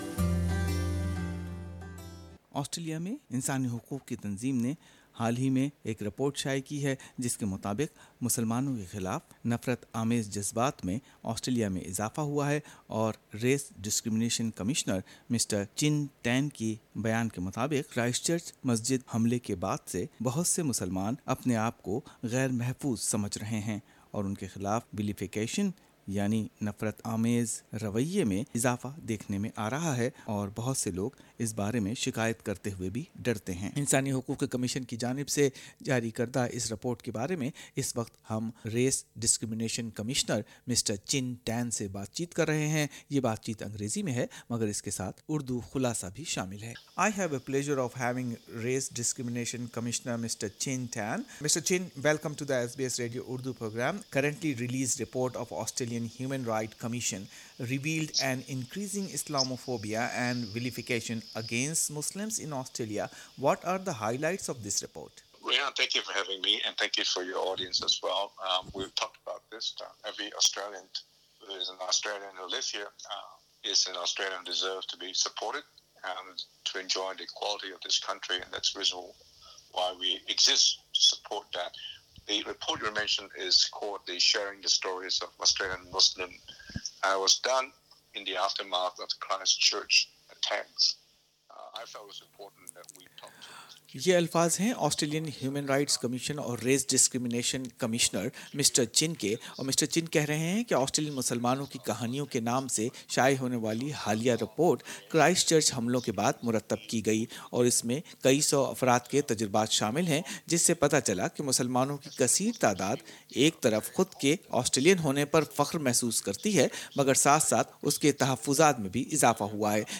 Australia’s Race Discrimination Commissioner Chin Tan, who initiated the project, told SBS Urdu that the survey report highlights community-identified solutions to the challenges raised in connection to each of its themes.